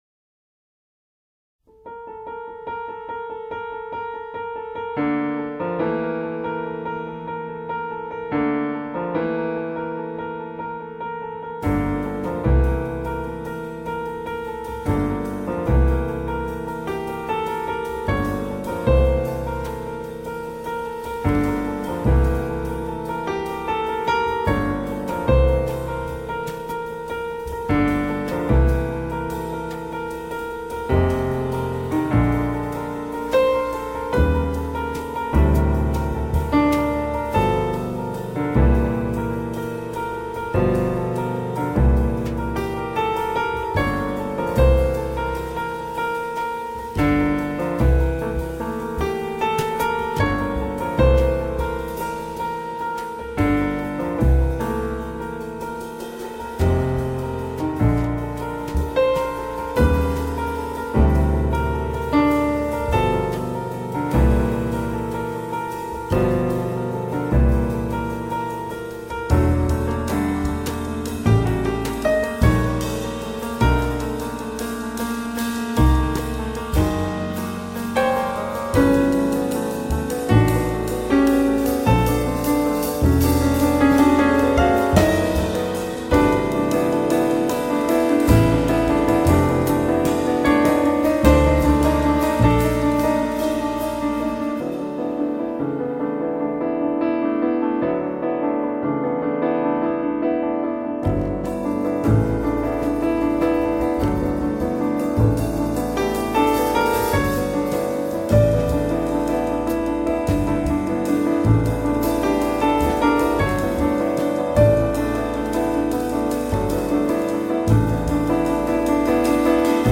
Download the Transcription: Piano Score + Bass